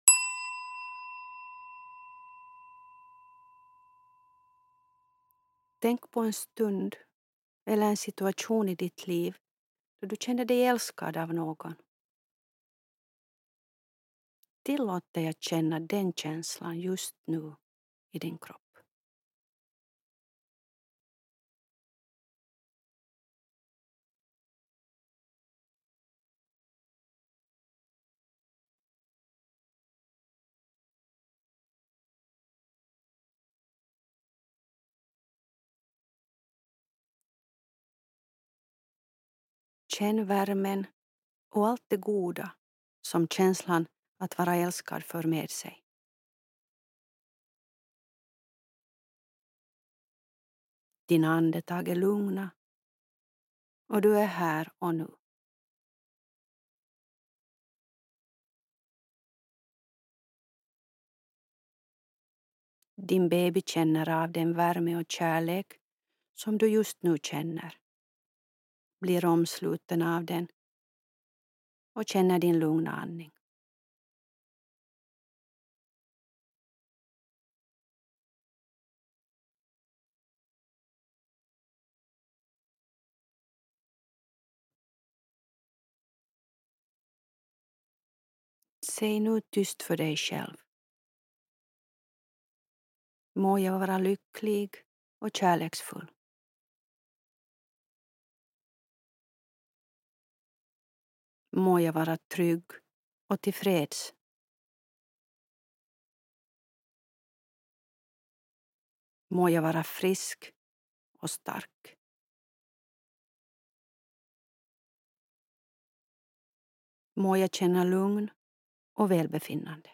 I avslappningsövningen nedan får du i tanken skicka kärleksfulla tankar till din baby och andra viktiga personer i ditt liv.